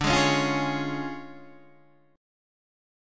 D Minor Major 7th Flat 5th